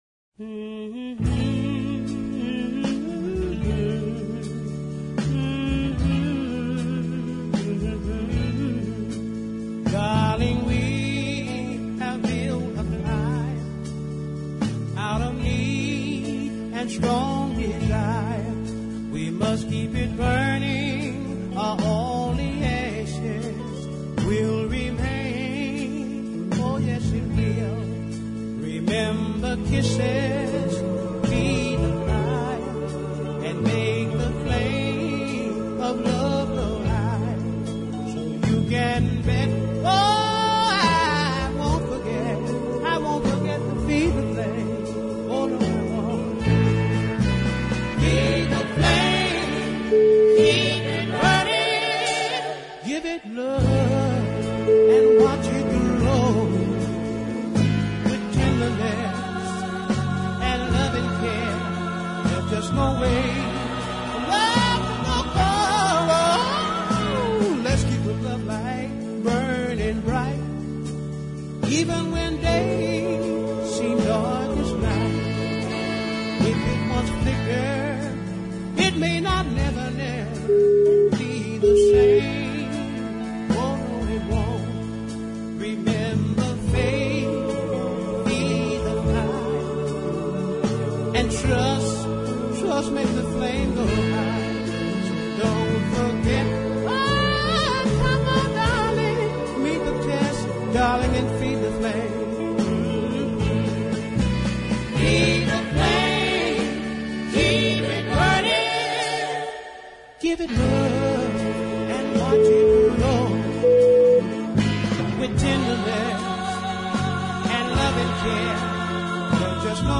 high-voiced soul-man
gorgeous lay-back country-soul song